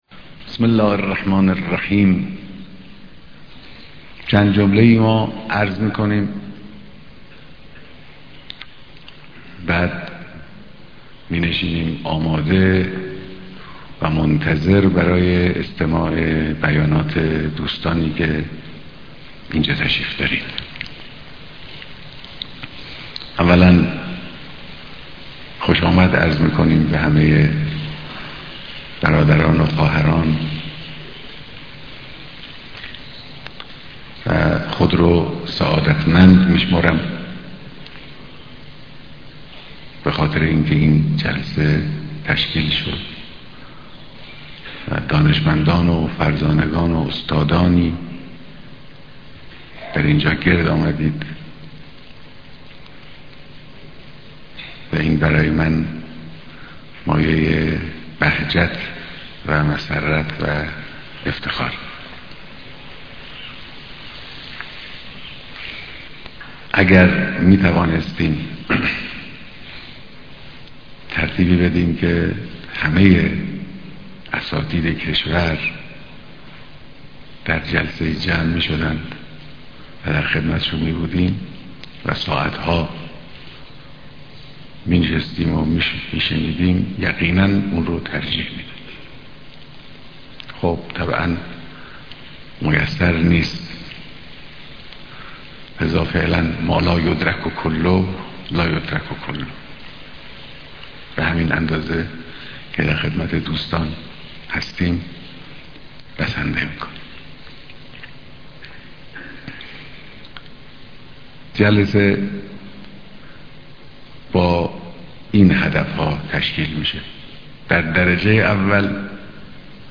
بيانات در ديدار اساتيد دانشگاه ها